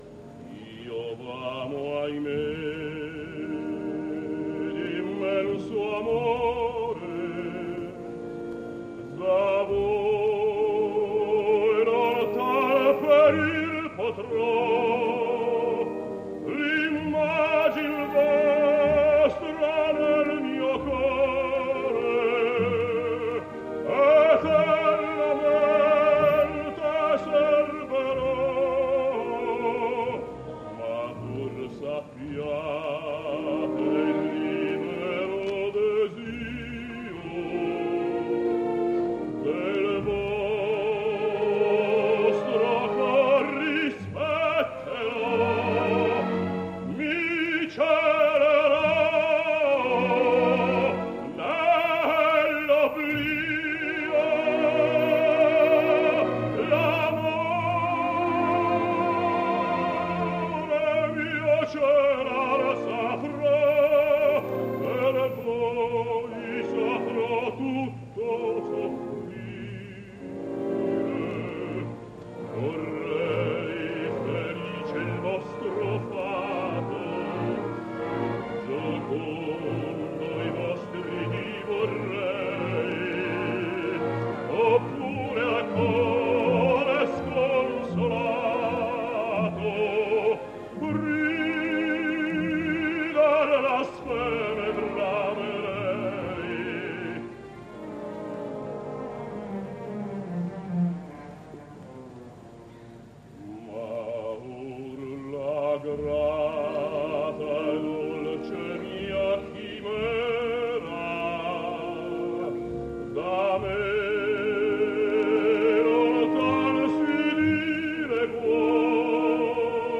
Из пропущенных дат: 10 декабря был день рождения известного итальянского баритона Сесто Брускантини (1919-2003гг)